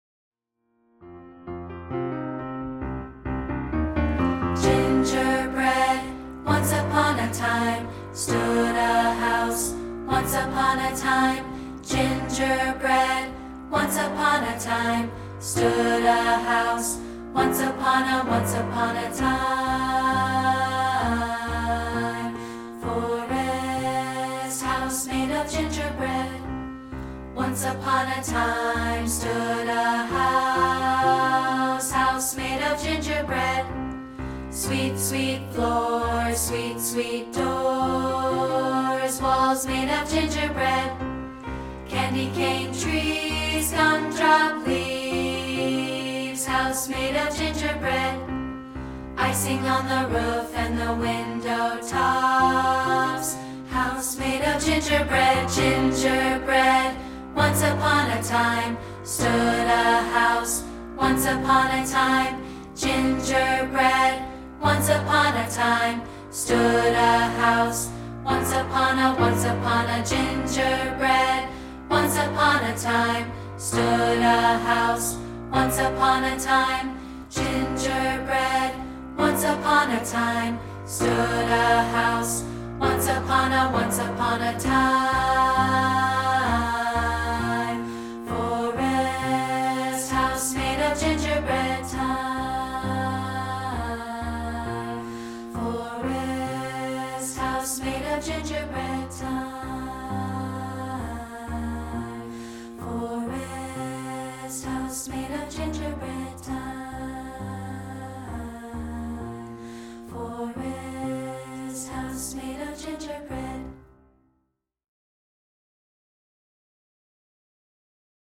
We've created a free rehearsal track of part 2, isolated